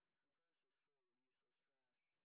sp03_street_snr30.wav